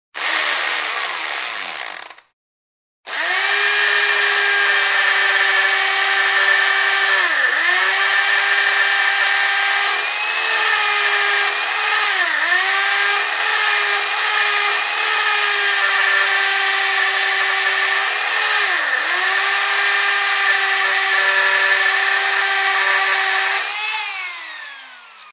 Все треки четкие и реалистичные.
Звук работающей дрели